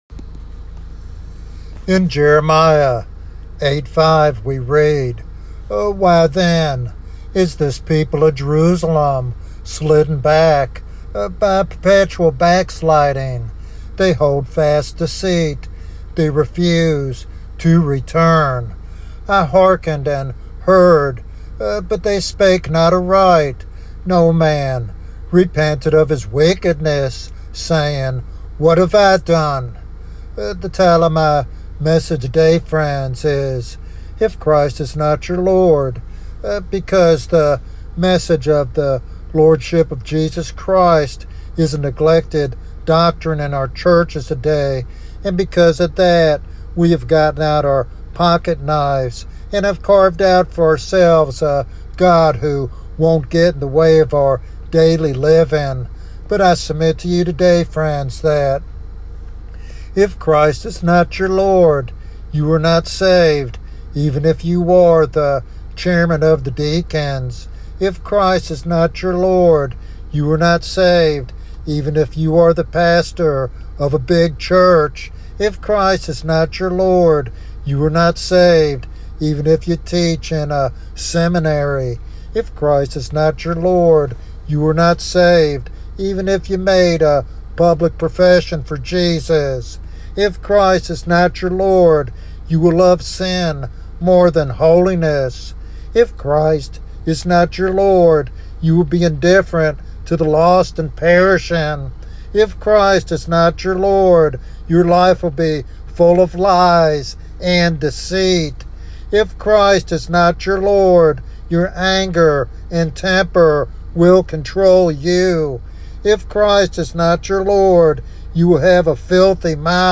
In this compelling sermon